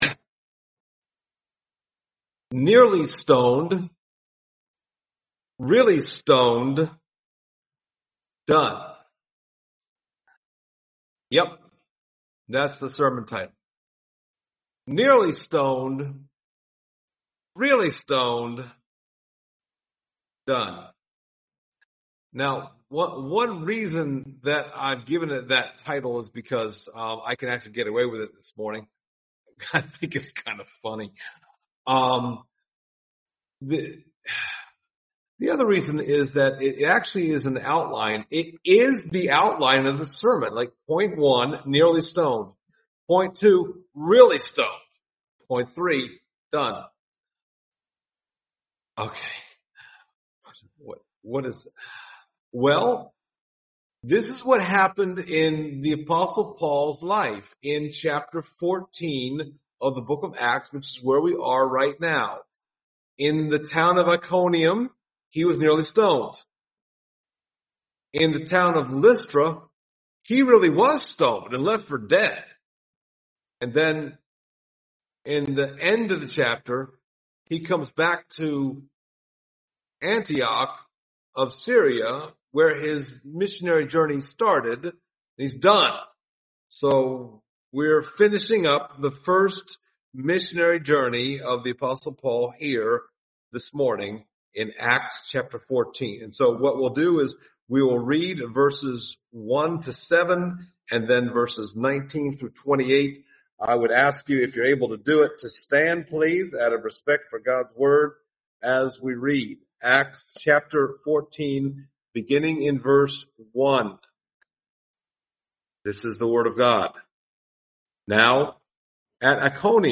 Service Type: Sunday Morning Topics: evangelism , miracles , opposition , persecution « Appointed to Eternal Life?